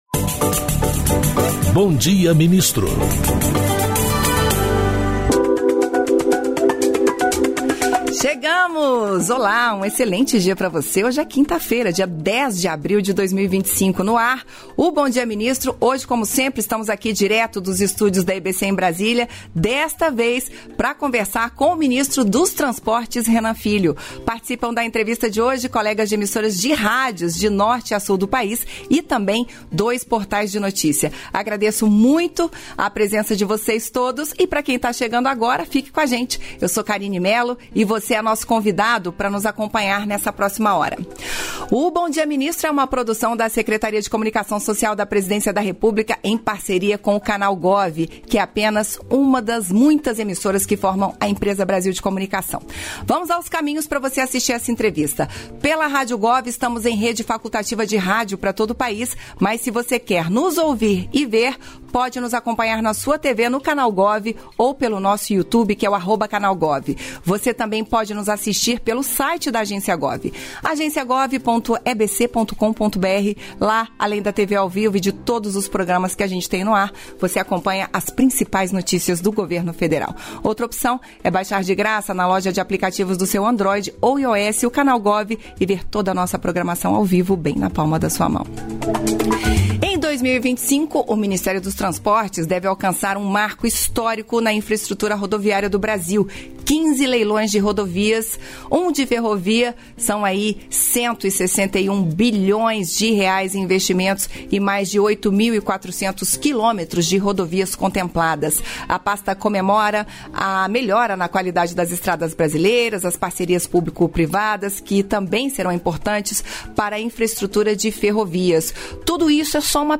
Íntegra da participação do ministro dos Transportes, Renan Filho, no programa "Bom Dia, Ministro" desta quinta-feira (10), nos estúdios da EBC, em Brasília (DF).